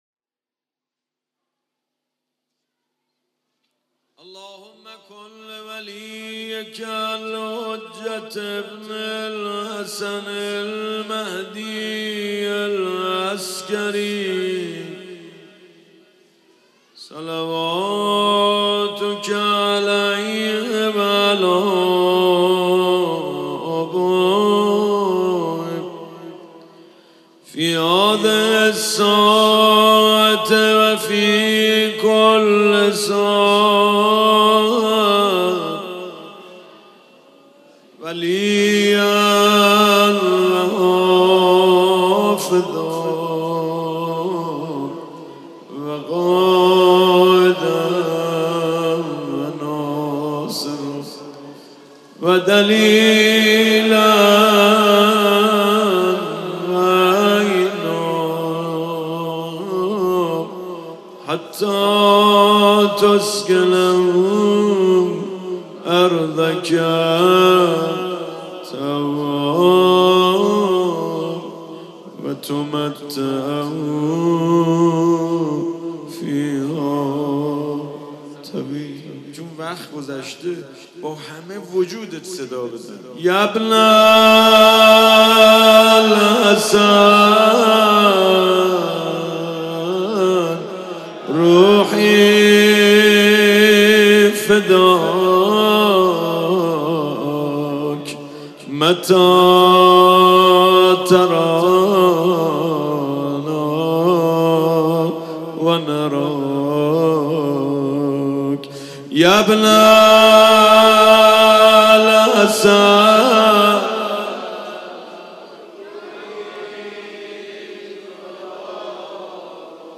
روضه خوانی
شب هفتم مجالس الحسنیه